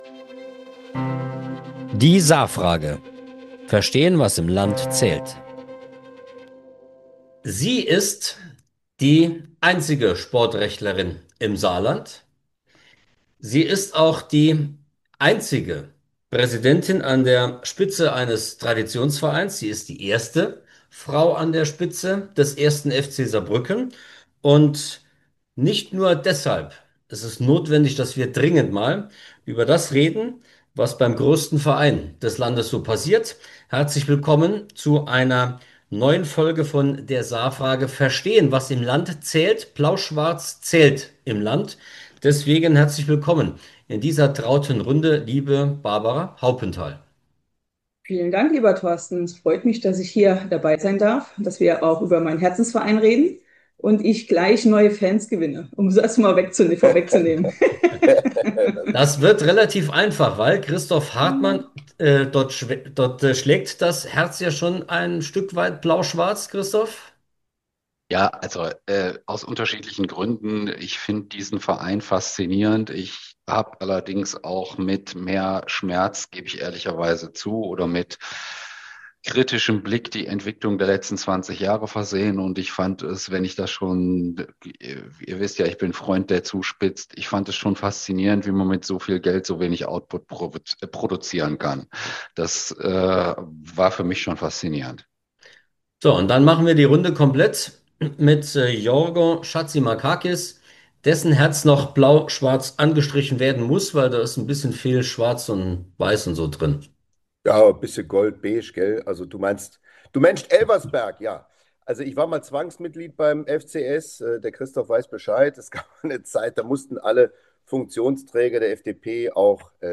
Ein Gespräch über Leidenschaft, Strukturreformen und die Kraft eines Vereins, der weit über das Stadion hinaus wirkt.